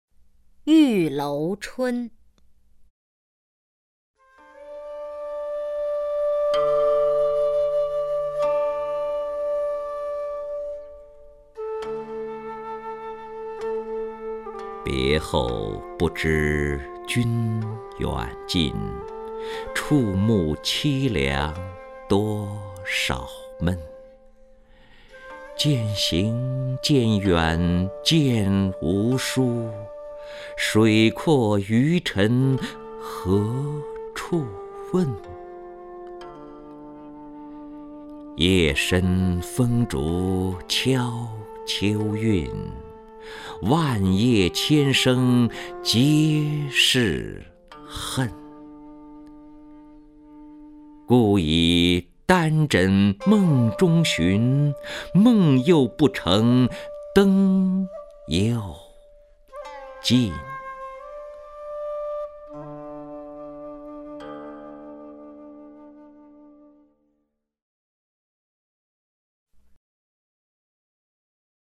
任志宏朗诵：《玉楼春·别后不知君远近》(（北宋）欧阳修)
名家朗诵欣赏 任志宏 目录